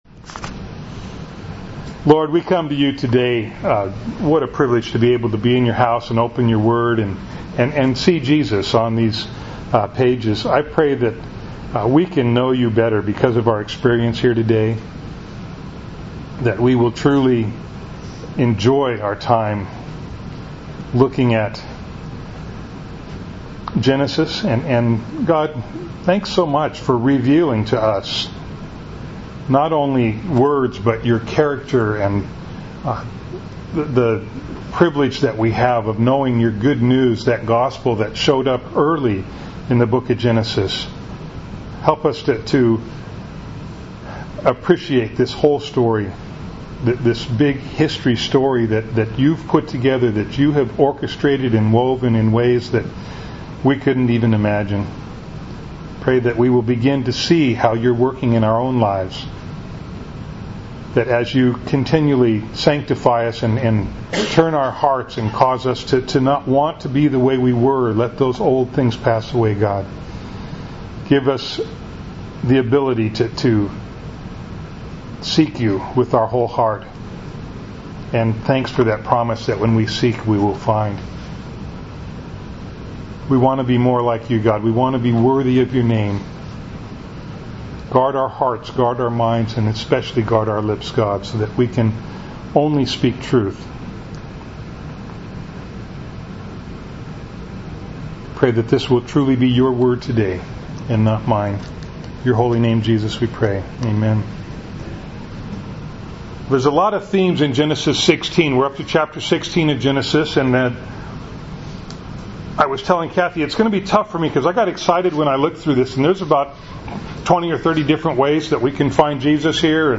Bible Text: Genesis 16:1-16 | Preacher